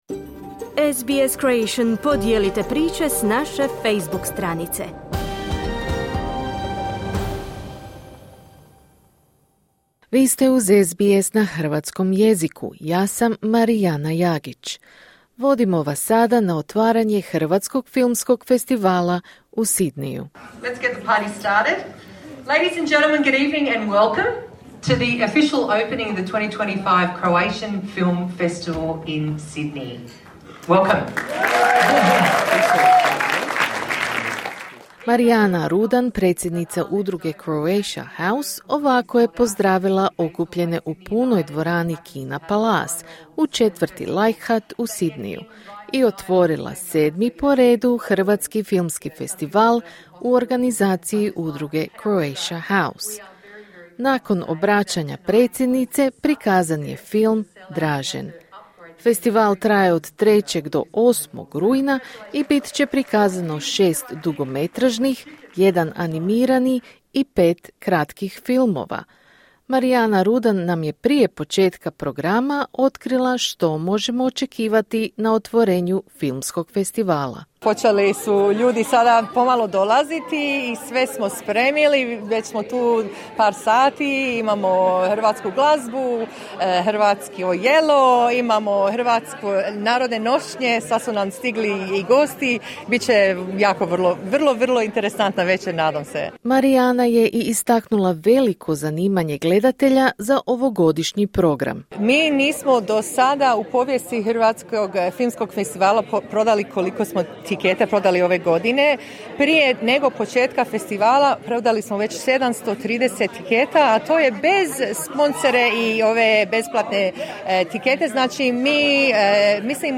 Sedmo izdanje Hrvatskog filmskog festivala u organizaciji udruge Croatia House traje od 3. do 8. rujna, a održava se u kinu Palace, u četvrti Leichardt. Veliko zanimanje publike za ovogodišnji program igranih, dokumentarnih i kratkih filmova bilo je vidljivo i na prvoj večeri festivala.